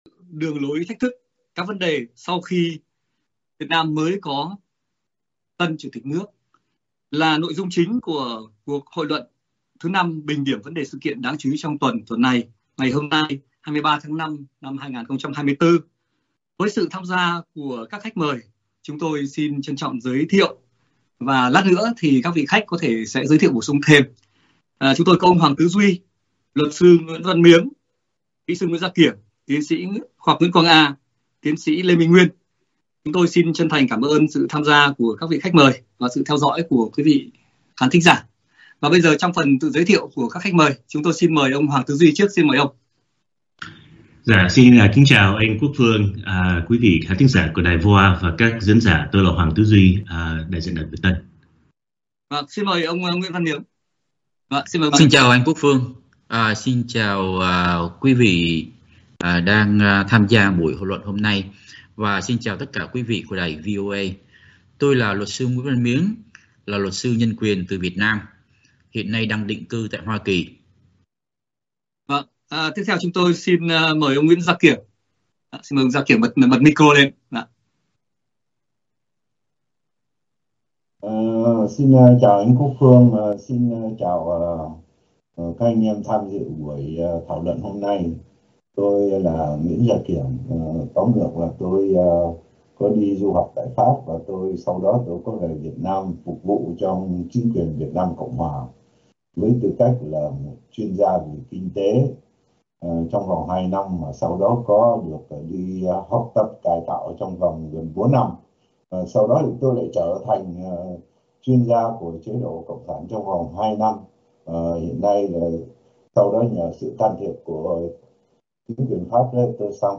Các khách mời là luật sư, chính trị gia, nhà phân tích, quan sát thời sự từ Việt Nam và hải ngoại phân tích tình hình chính trị Việt Nam, những vấn đề, thách thức mà ban lãnh đạo cấp cao của chính quyền và ĐCSVN đang đối diện hoặc còn phải đương đầu dù mới có thêm tân Chủ tịch nước.